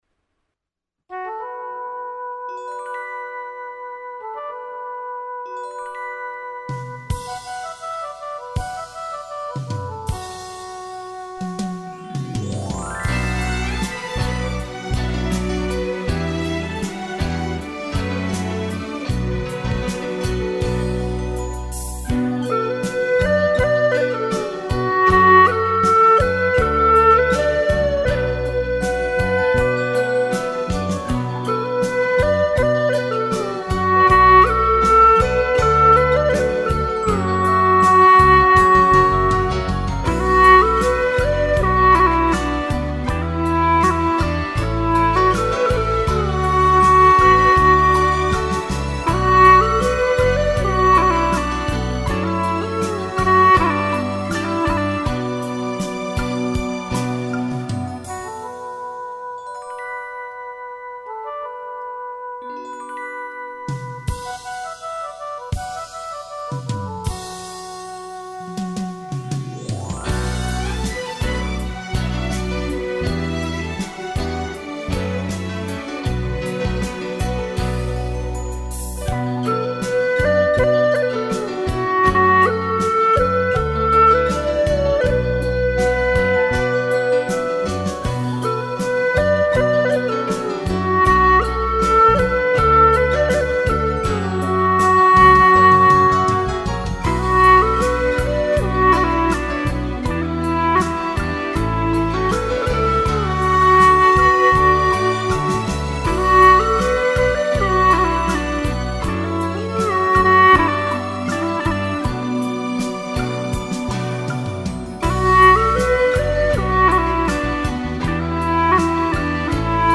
调式 : D 曲类 : 民族
民族味道好浓！